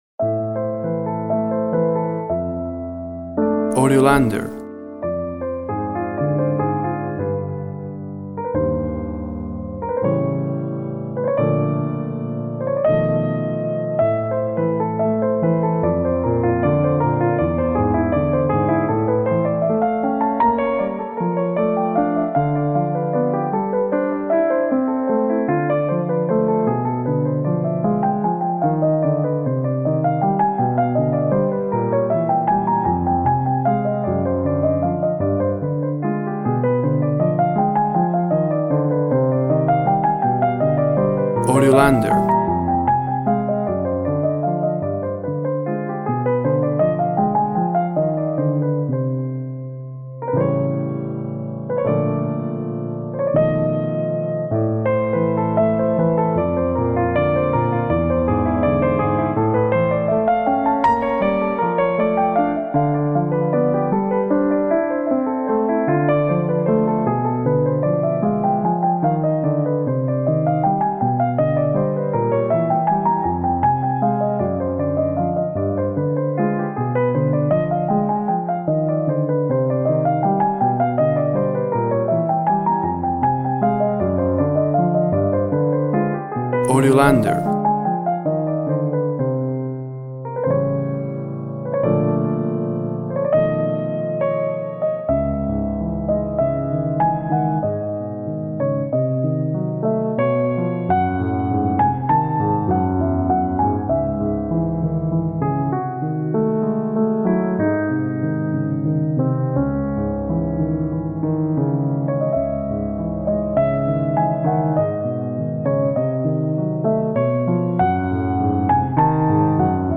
Piano Emotive ,Classical piece with tango influences.
Tempo (BPM) 60